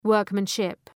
Προφορά
{‘wɜ:rkmən,ʃıp}